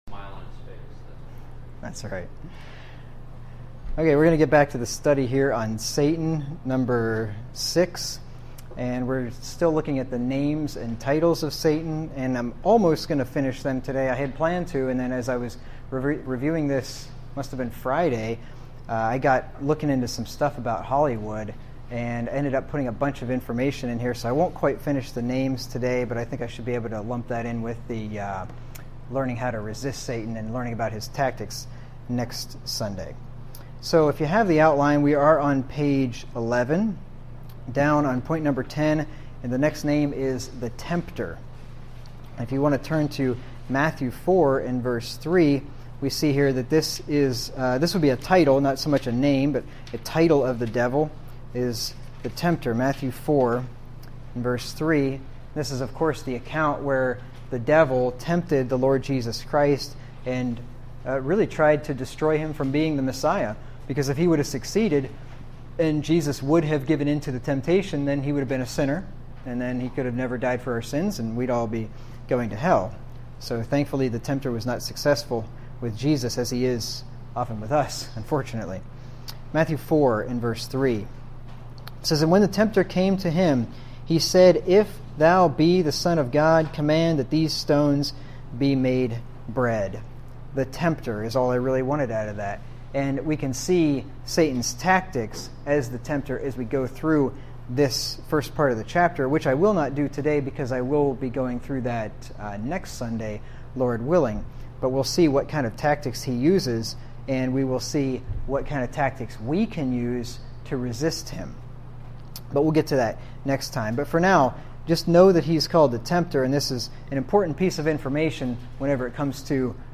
Format: MP3 Stereo 32 kHz 71 Kbps (ABR) Watch the video of this sermon on YouTube